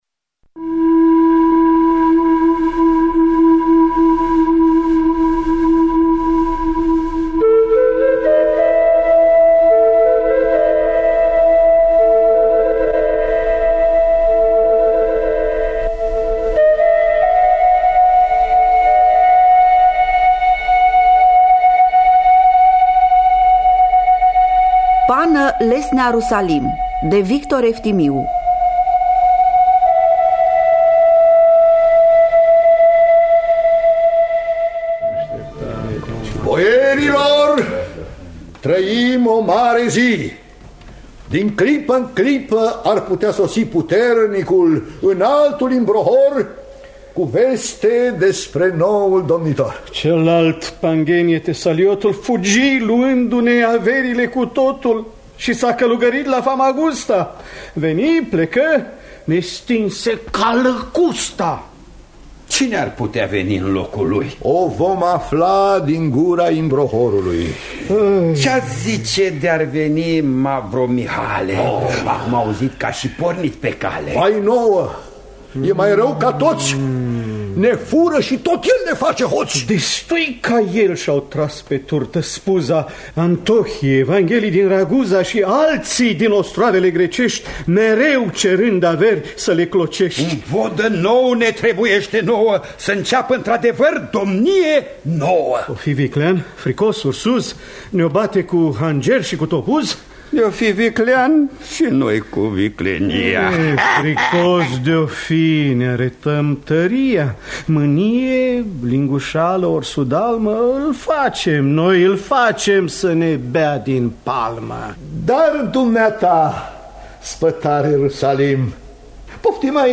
„Pană Lesnea Rusalin” de Victor Eftimiu – Teatru Radiofonic Online